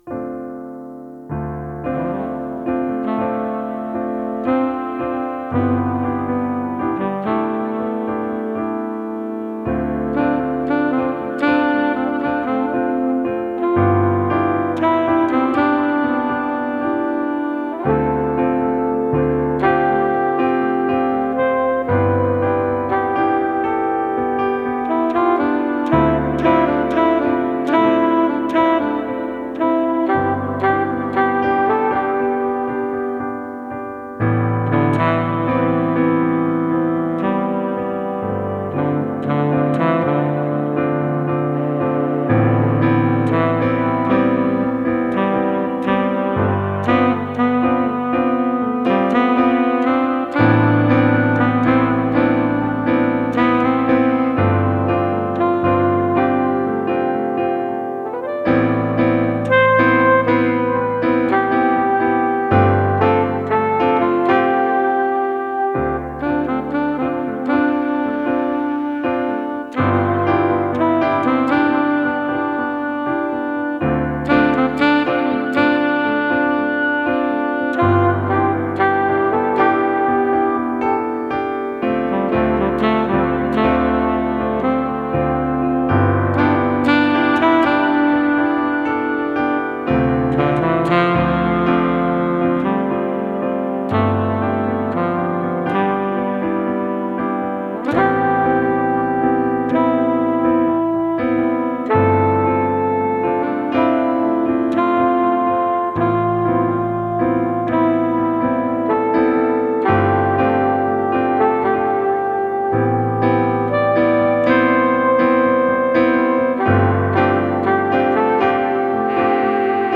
Slow jazzy Piano with Saxophon.